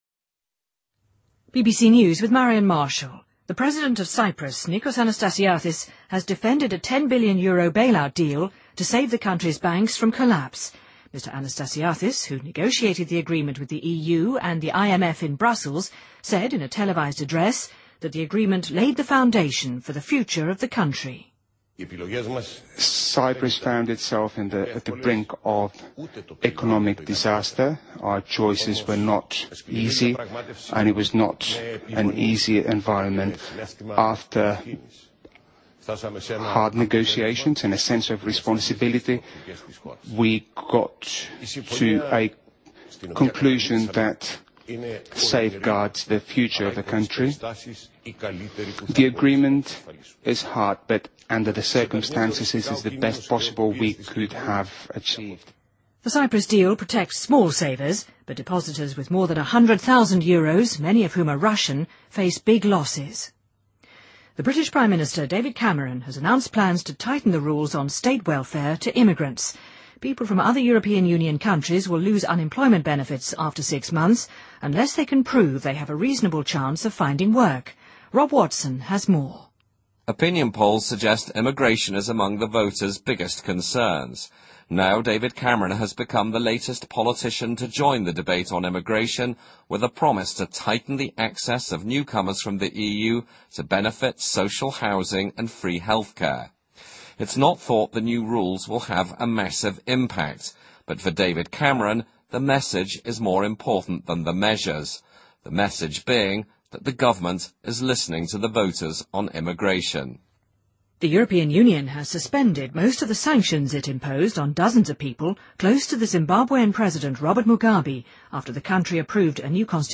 BBC news,2013-03-26